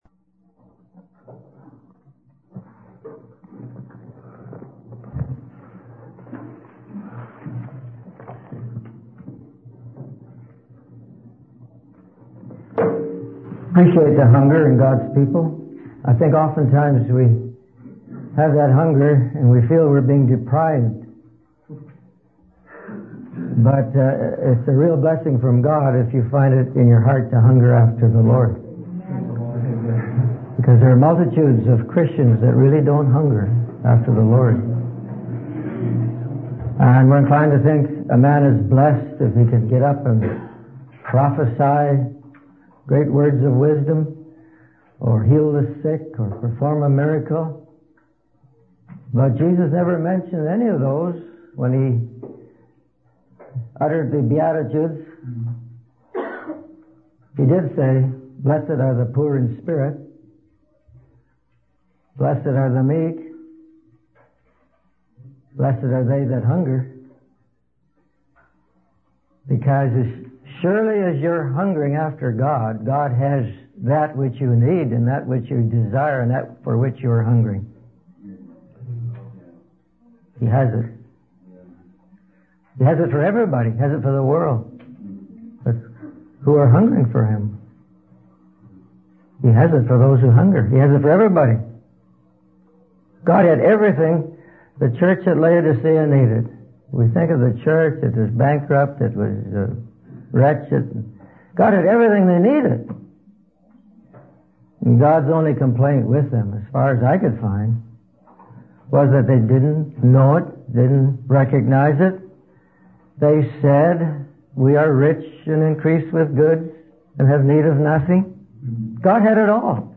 In this sermon, the speaker reflects on the importance of knowing God's ways and the need to avoid erring in our hearts.